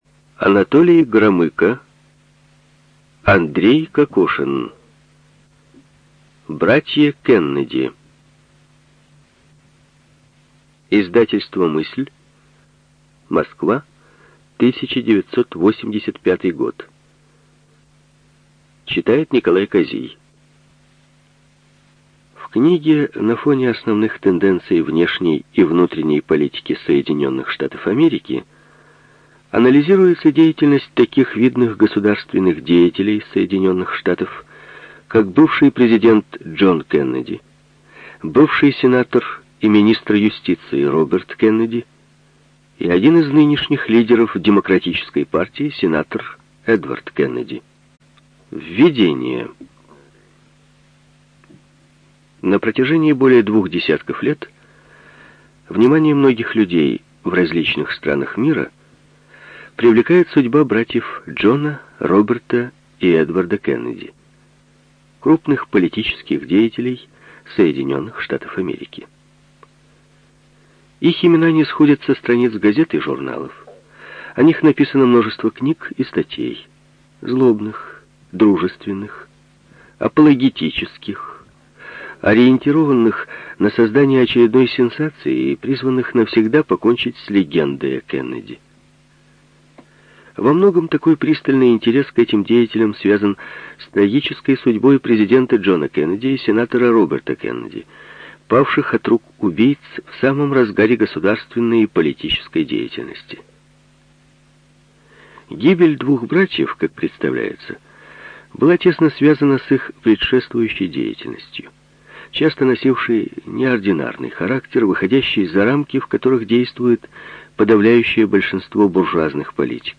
ЖанрБиографии и мемуары, Документальные фонограммы
Студия звукозаписиРеспубликанский дом звукозаписи и печати УТОС